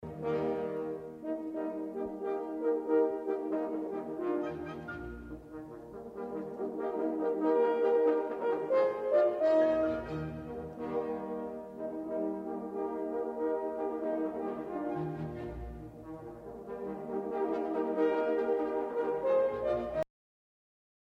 Il moderno corno ha tre pistoni, un canneggio circolare di ottone che termina da un lato con un'ambia svasatura a campana e dall'altro con un bocchino ad imbuto che dà al corno il suo caratteristico timbro soffice e vellutato.
corni in orchestra
corni.mp3